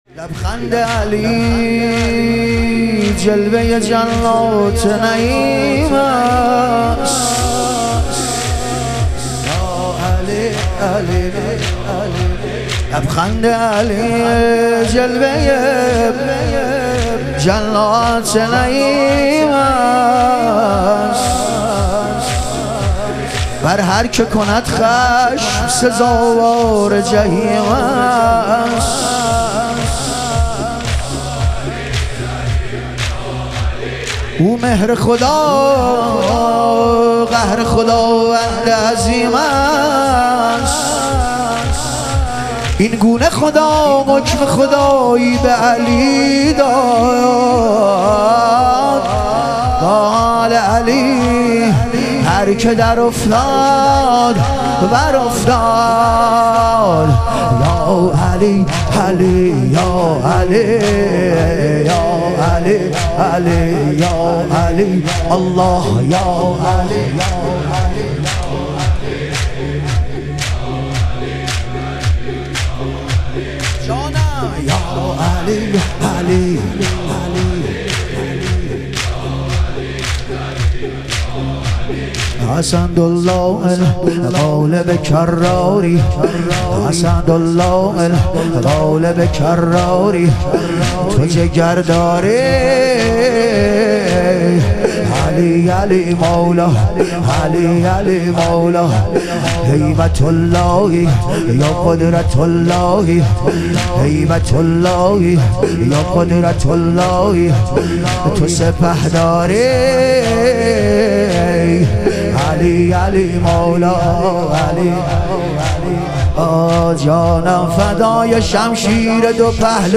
ایام فاطمیه دوم - تک